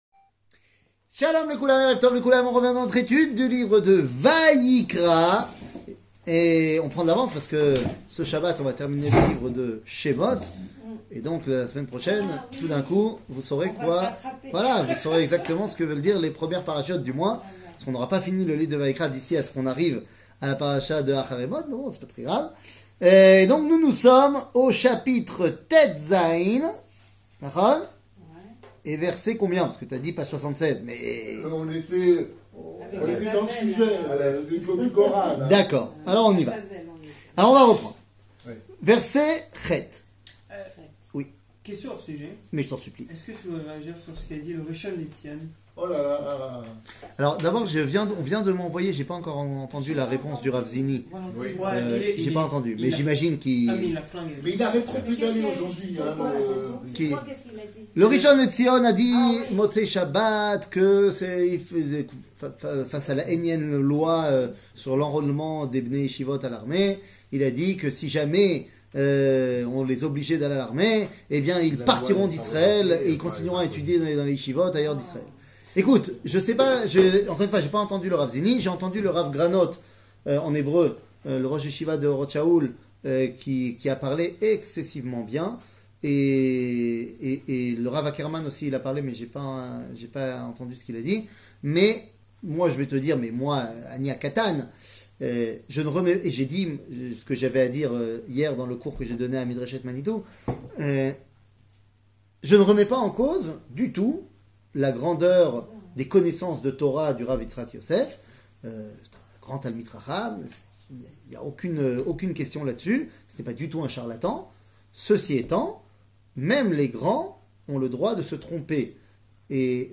קטגוריה Livre de Vayikra 15 00:52:32 Livre de Vayikra 15 שיעור מ 13 מרץ 2024 52MIN הורדה בקובץ אודיו MP3